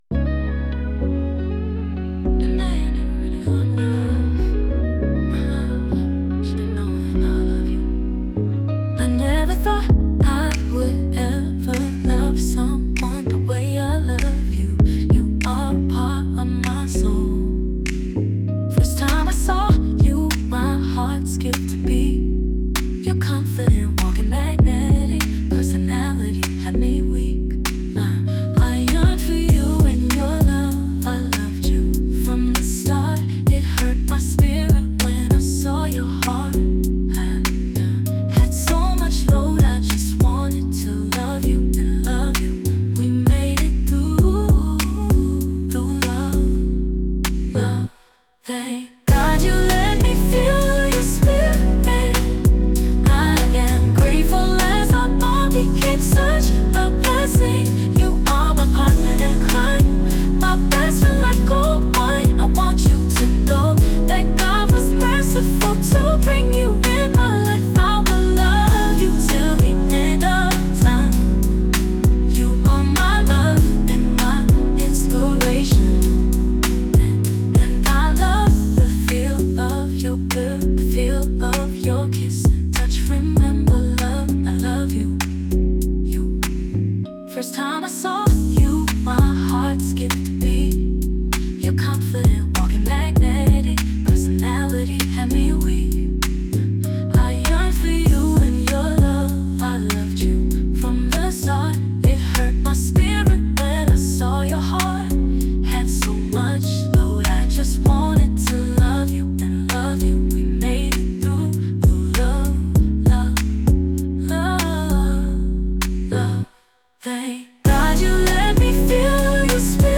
R&B-pop